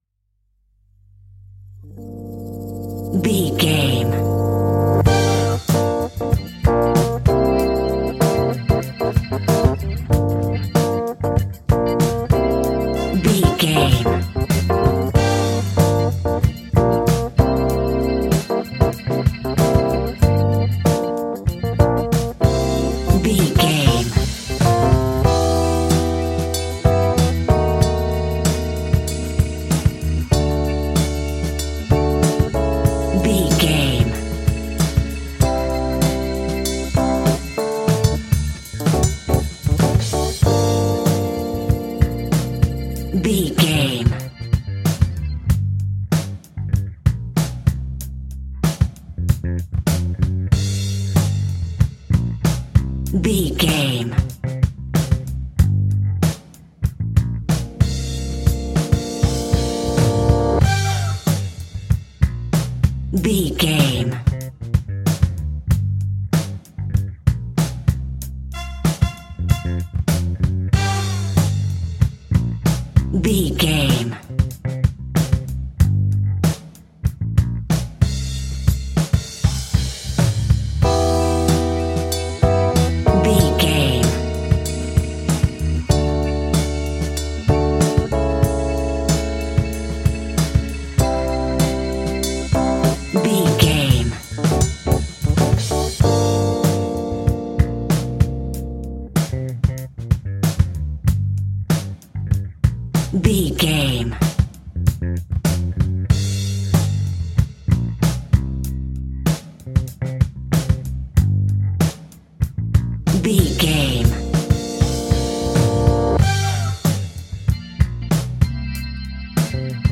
Aeolian/Minor
funky
uplifting
bass guitar
electric guitar
saxophone